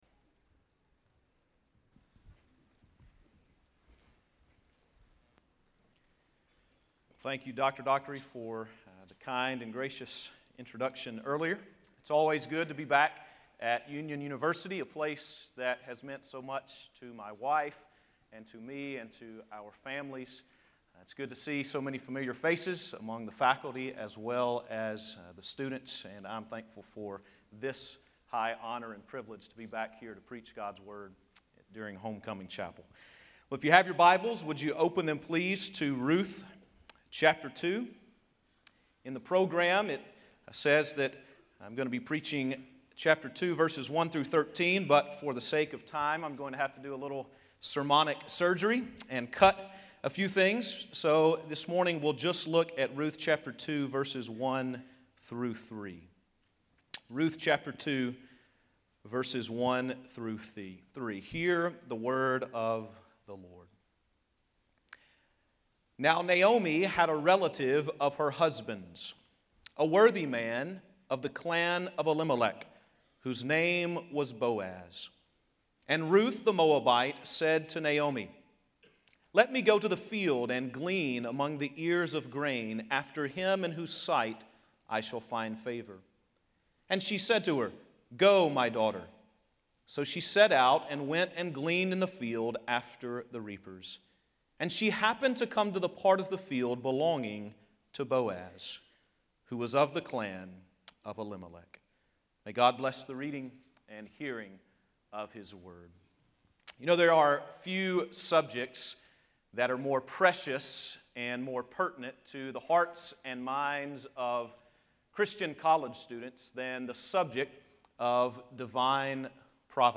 Homecoming Chapel